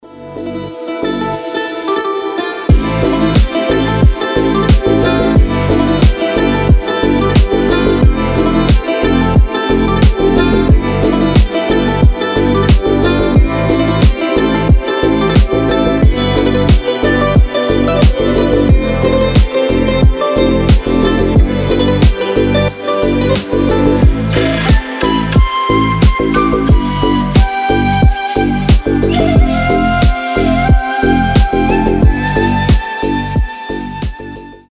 гитара
deep house
без слов
красивая мелодия
восточные
Флейта
oriental house